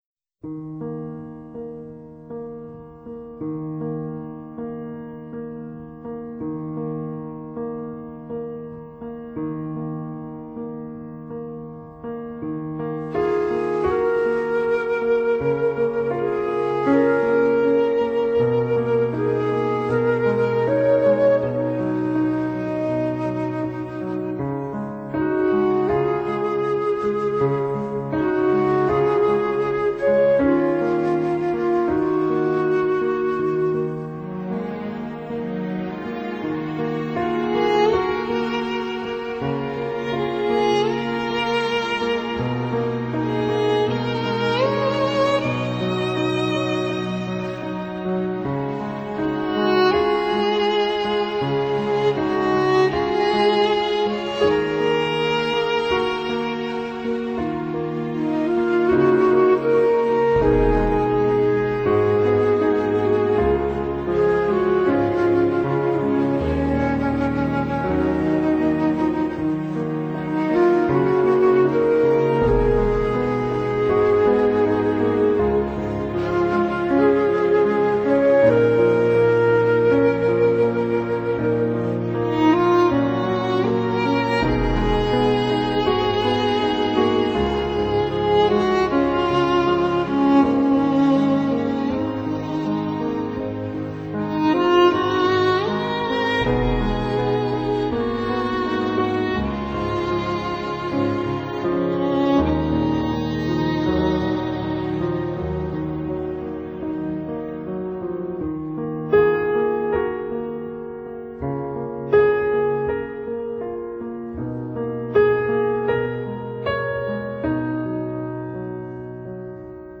新世紀樂壇的夢幻組合
除展現最擅常的極簡派田園詩情